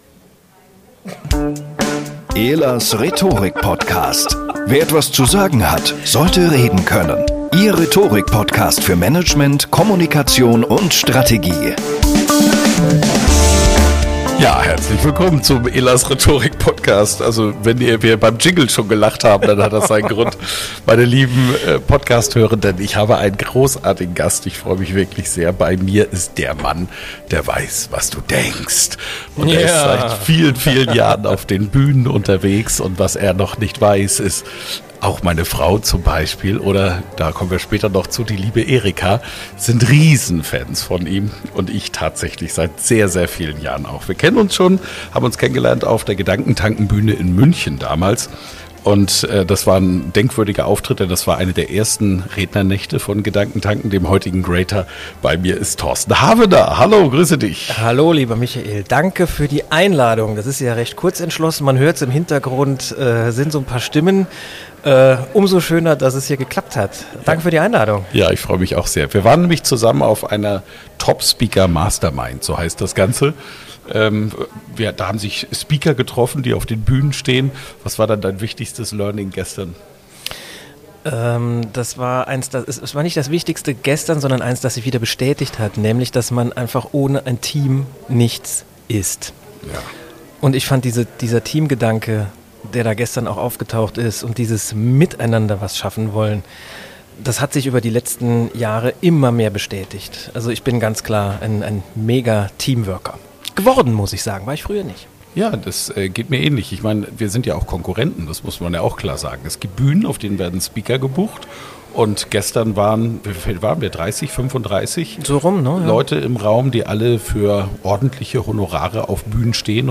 Bei einem Treffen in Köln haben wir uns nach einem gemeinsamen Frühstück im Hotel Co-Working-Space zum Interview getroffen.